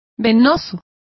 Complete with pronunciation of the translation of venous.